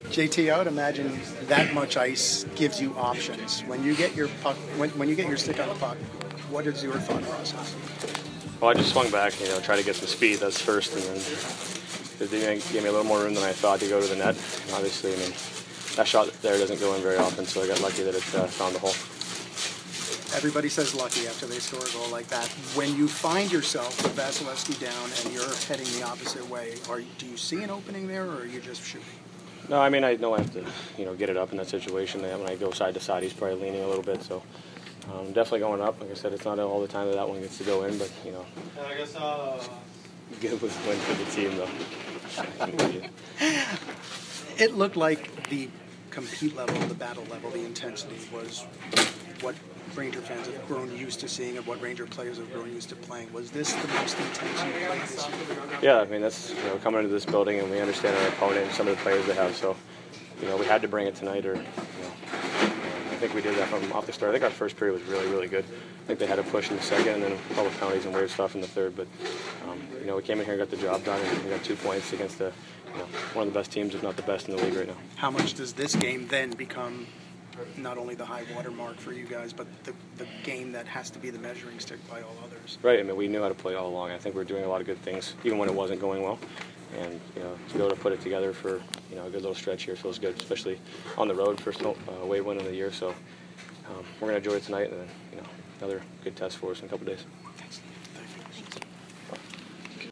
J.T. Miller Post-Game 11/2